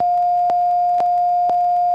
Radar Warning Receiver